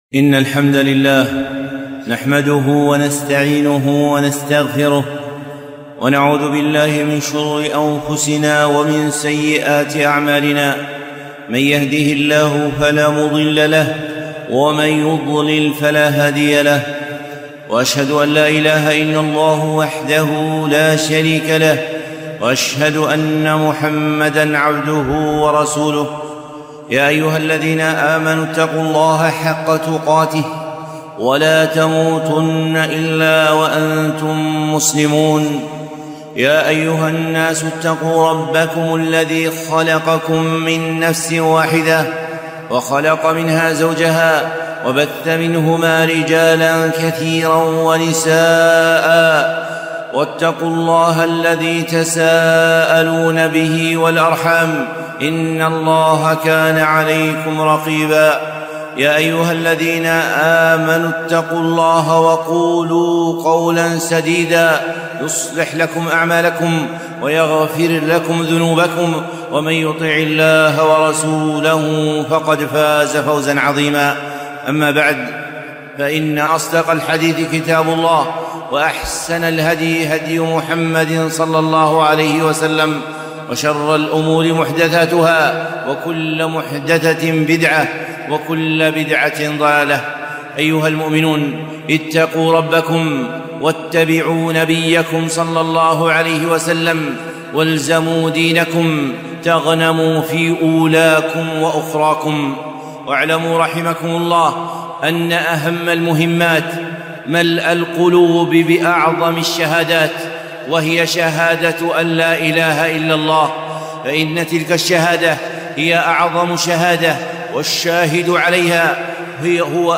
خطبة - أعظم الشهادات